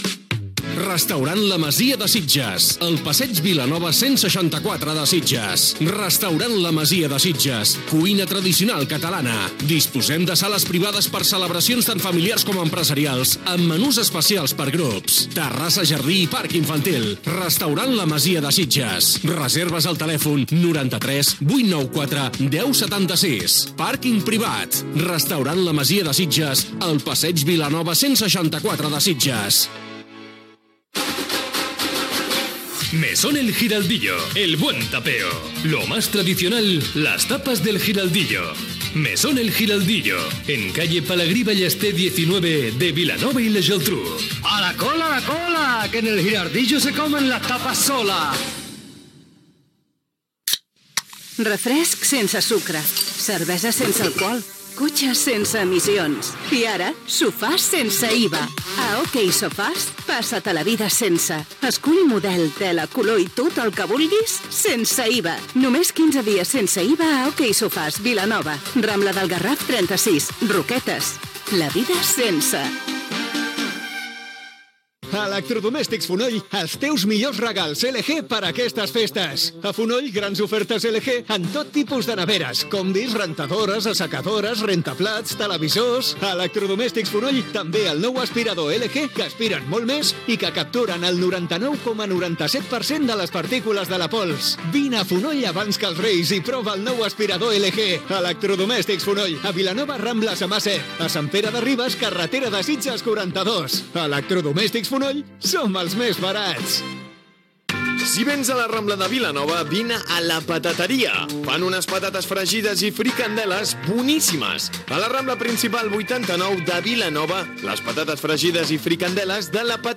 Publicitat, promocions dels programes "Nits de radio" i "En bones mans", indicatiu de l'emissora
FM